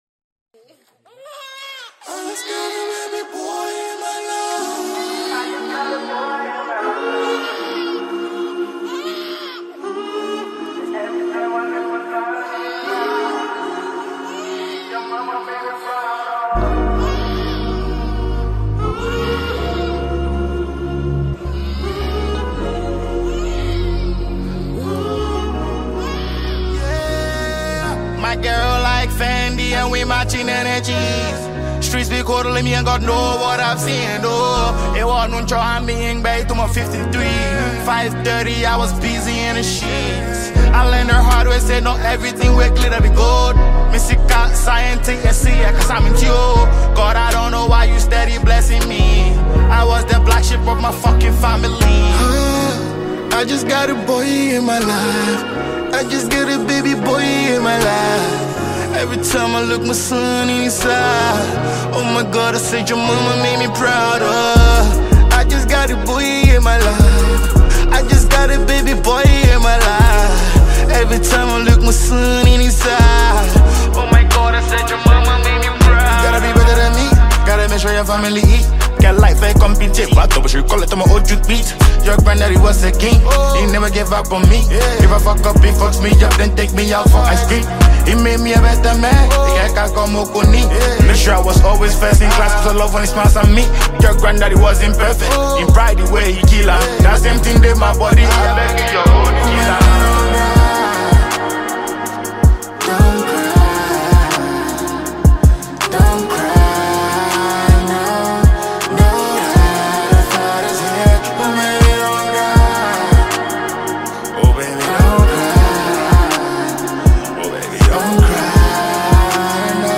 Ghanaian afrobeat sensation